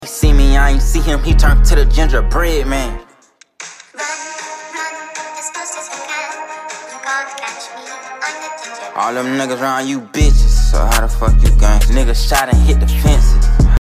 raining sound effects free download